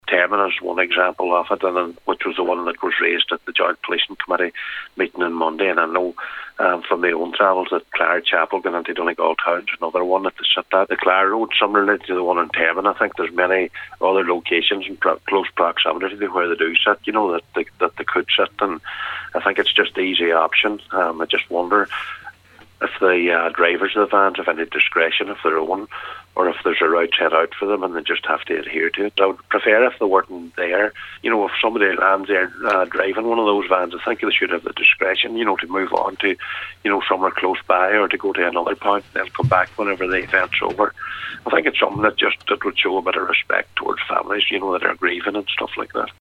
Cllr Michael Mc Bride raised the issue, and on today’s Nine til Noon Show, he said in most cases , there are ample alternative locations.